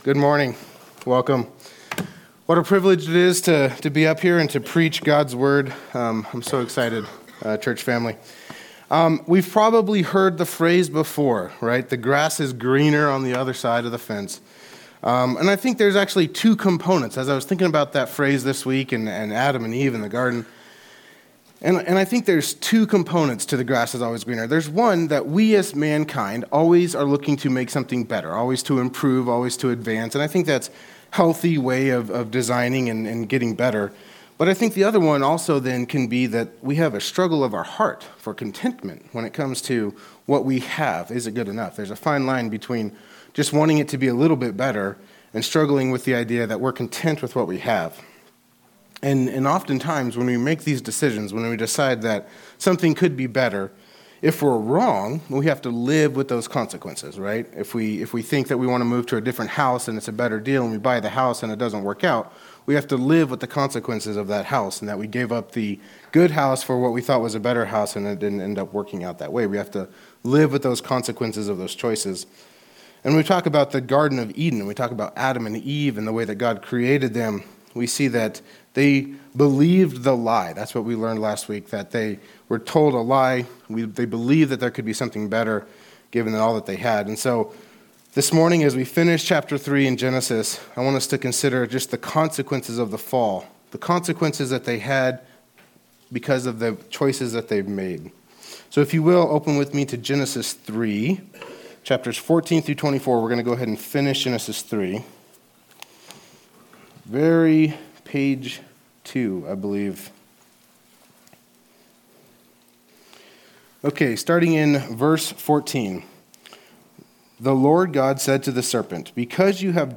Message
Location: High Plains Harvest Church